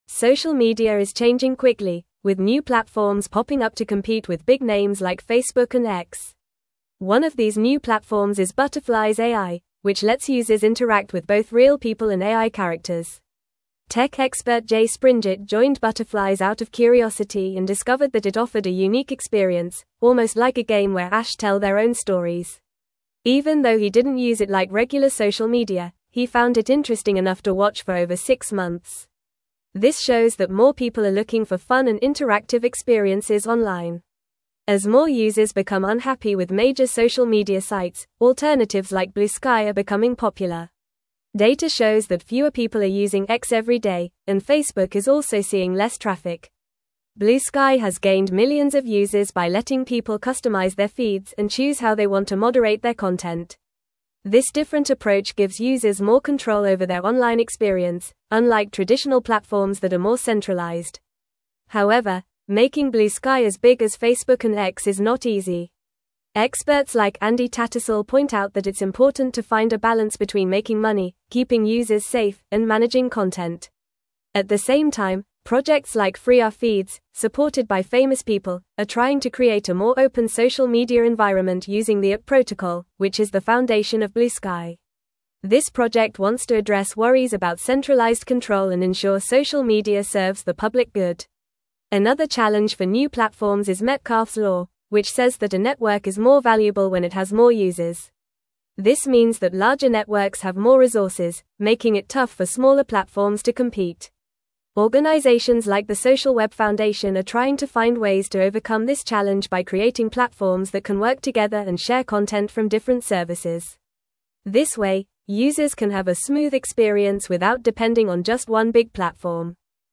Fast
English-Newsroom-Upper-Intermediate-FAST-Reading-Emerging-Social-Media-Platforms-Challenge-Established-Giants.mp3